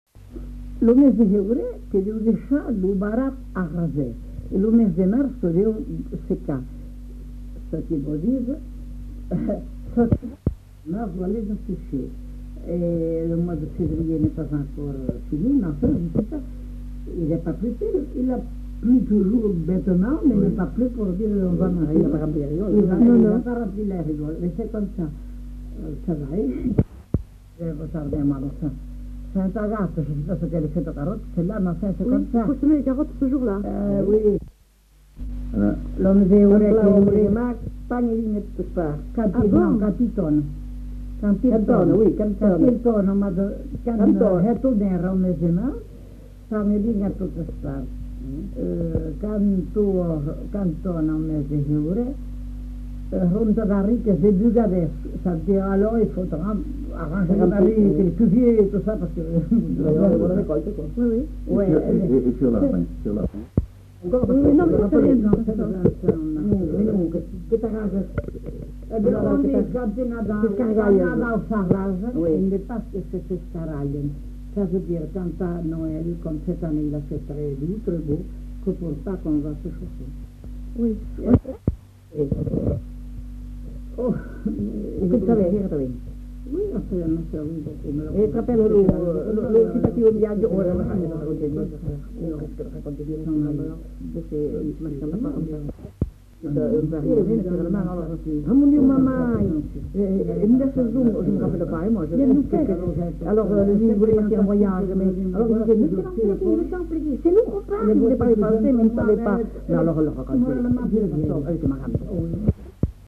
Aire culturelle : Marsan
Genre : forme brève
Effectif : 1
Type de voix : voix de femme
Production du son : récité
Classification : proverbe-dicton